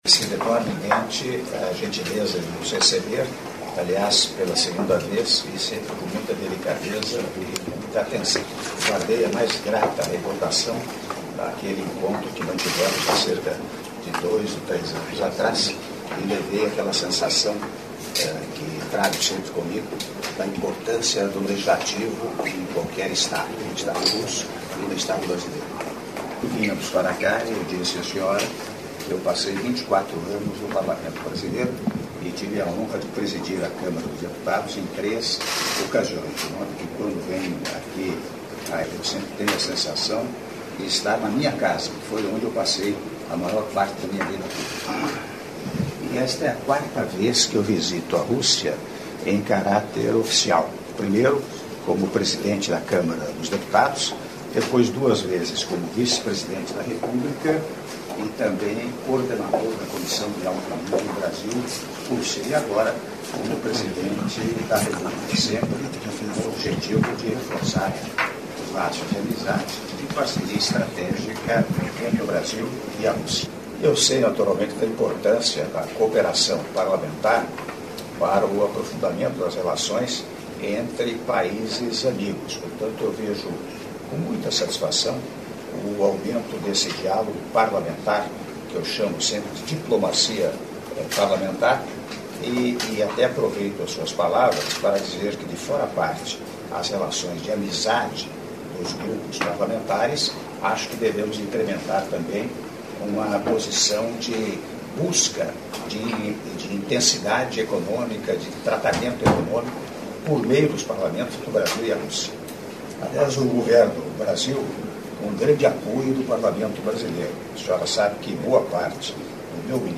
Áudio do discurso do Presidente da República, Michel Temer, durante encontro com a Senadora Valentina Matvienko, Presidente do Conselho da Federação da Rússia - Moscou/Rússia (05min55s)